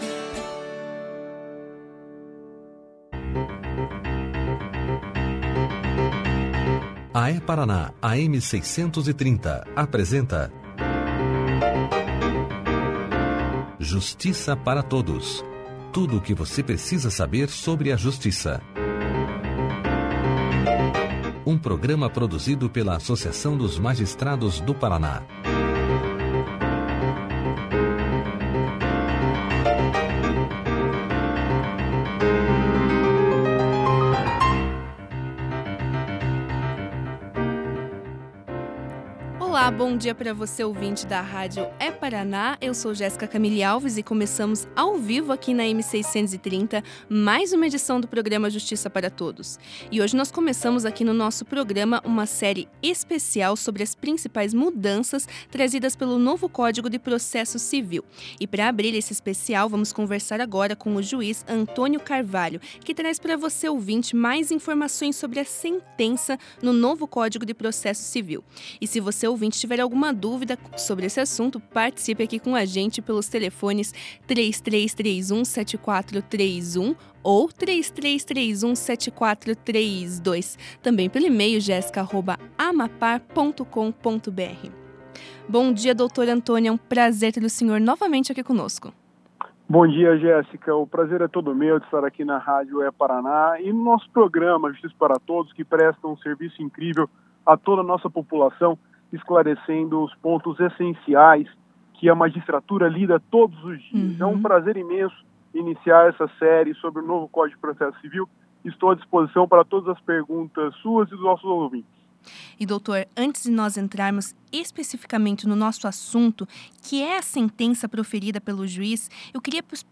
Clique aqui e ouça a entrevista do juiz Antônio Carvalho sobre a sentença no Novo Código de Processo Civil na íntegra.